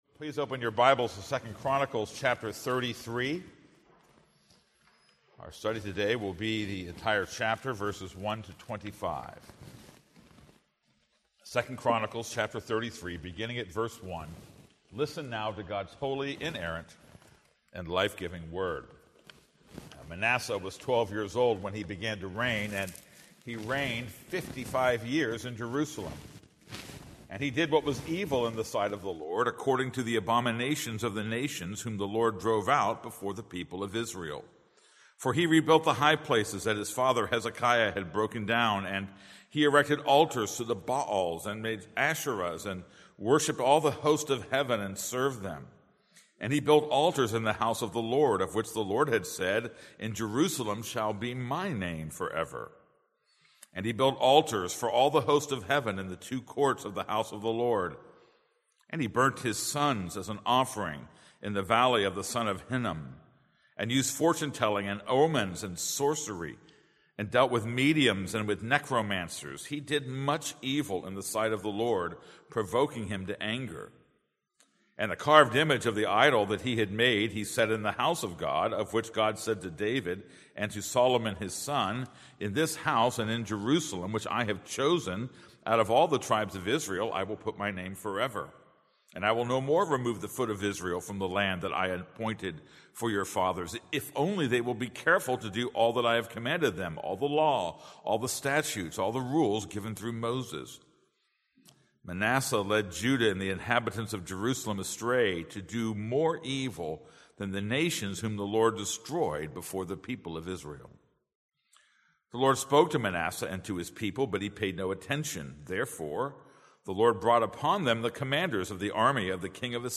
This is a sermon on 2 Chronicles 33:1-25.